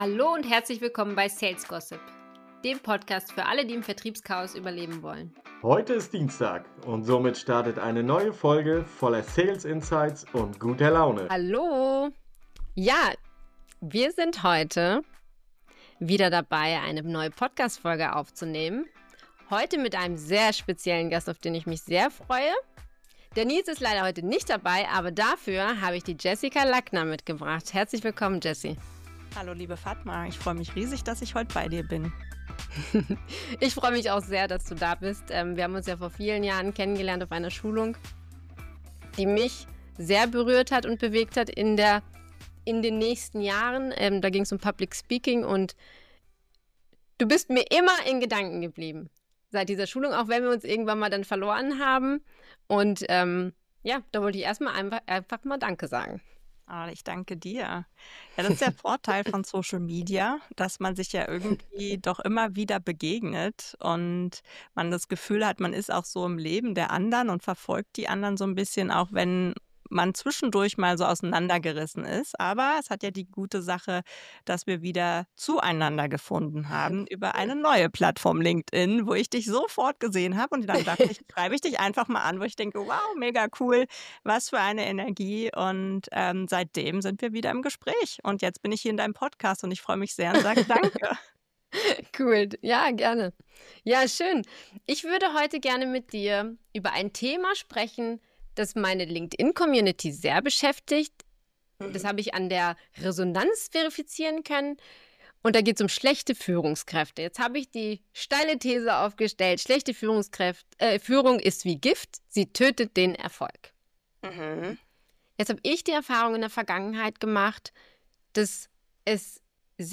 Interview!